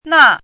怎么读
纳 [nà]
na4.mp3